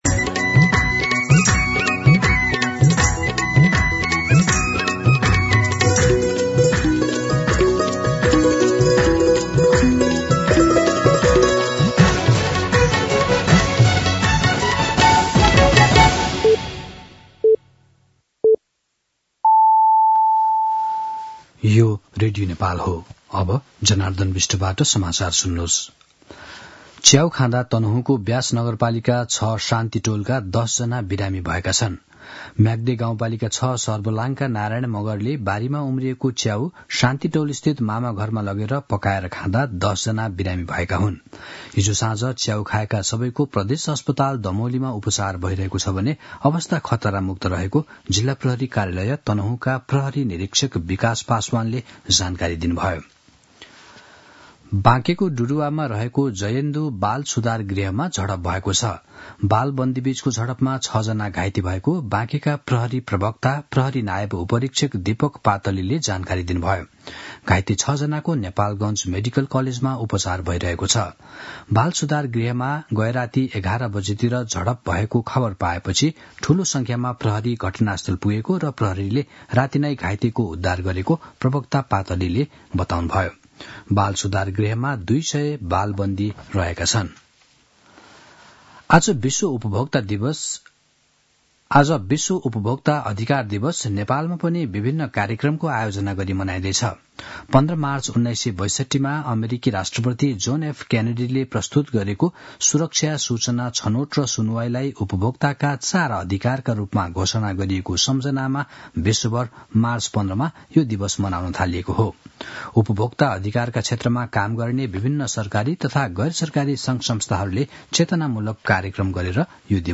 दिउँसो १ बजेको नेपाली समाचार : २ चैत , २०८१
1-pm-Nepali-News.mp3